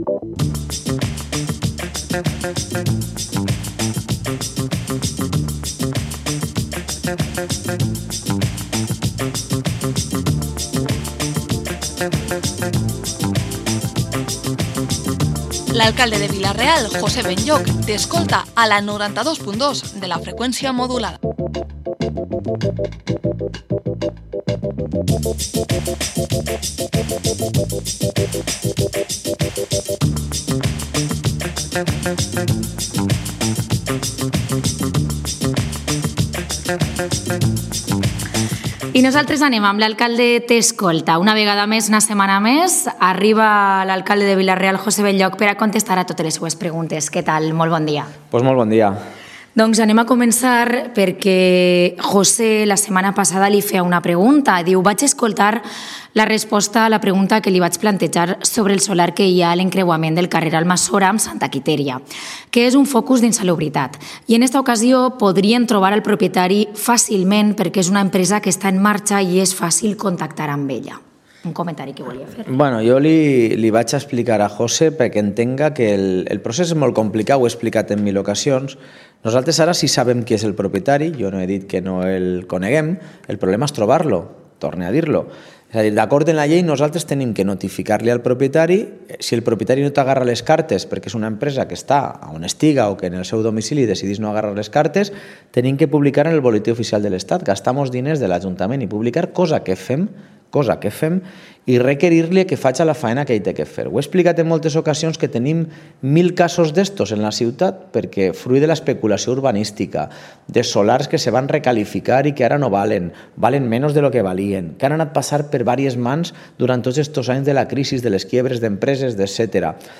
Espacio semanal dedicado al ciudadano, que podrá preguntar y exponer sus inquietudes al Alcalde de Vila-real, José Benlloch. Cada semana responderá en directo a cuestiones planteadas por nuestros oyentes.